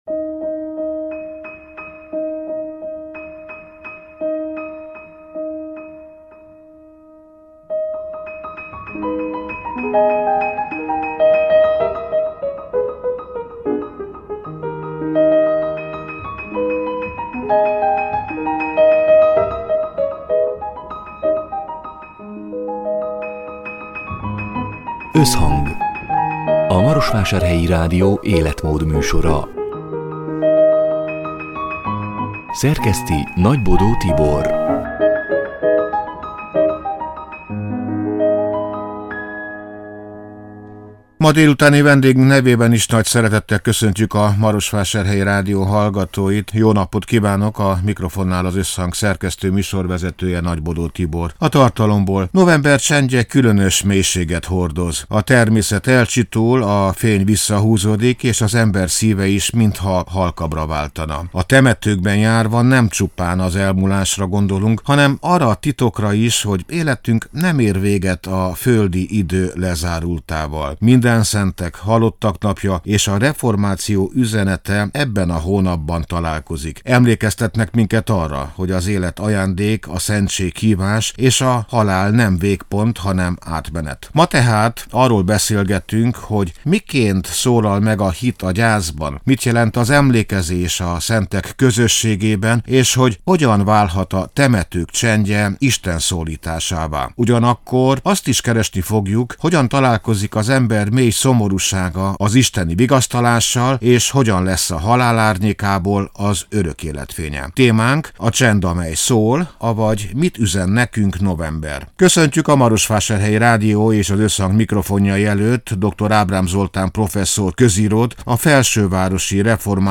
(elhangzott: 2025. november 12-én, szerdán délután hat órától élőben)